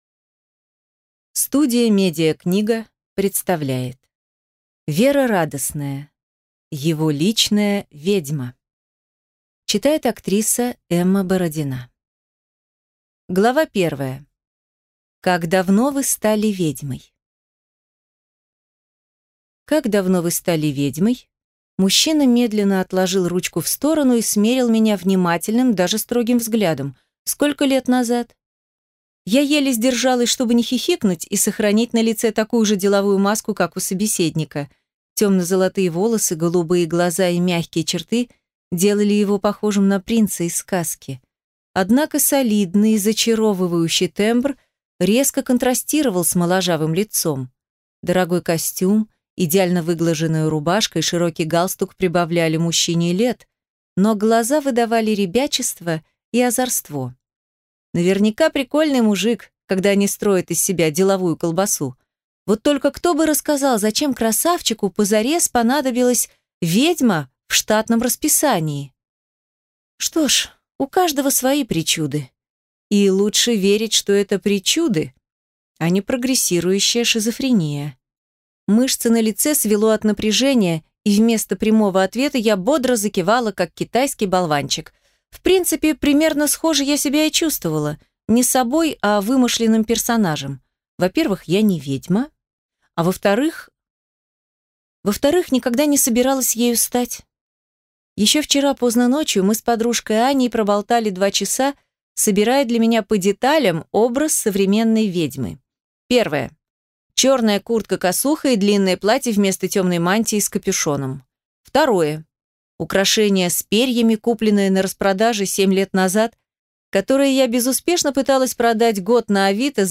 Аудиокнига Его личная ведьма | Библиотека аудиокниг